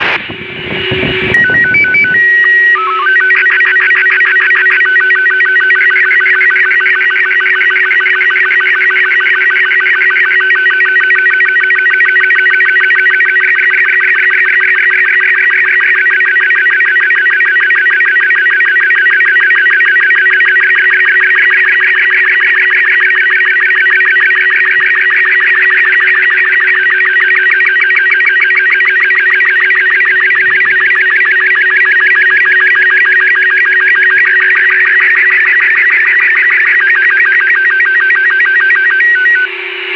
The unique features of PSAT-2 are the APRS transponder and the PSK31 transponder, both of which flew on PSAT-1 and the new APRStt (TouchTone)/Voice transponder, for which a prototype flies on the eXCITe (QIKCOM-2) satellite, which lets everyone do APRS using any radio with DTMF keypad, not just those with APRS radios. in addition it features a SSTV (Robot-36 format) downlink.
435.350 MHz – Downlink PSK31 und SSTV